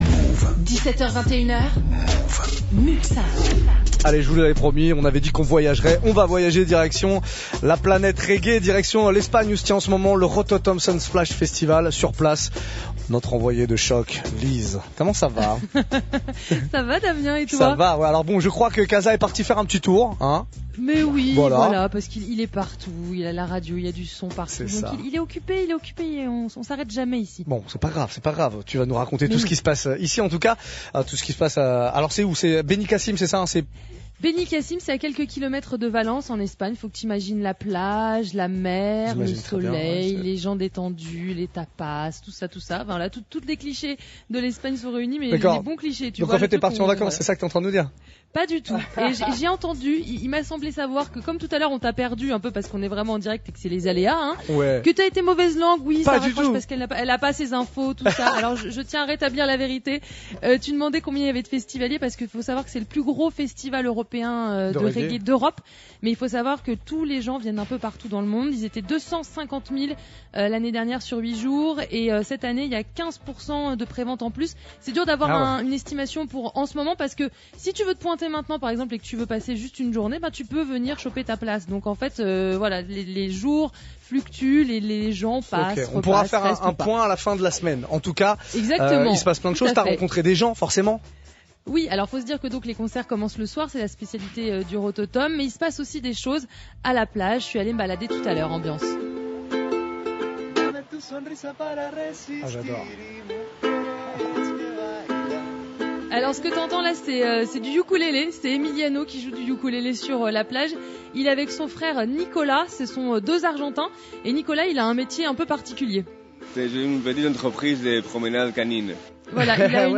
Rototom 2016, directs depuis le plus grand festival de reggae d’Europe (Mouv’)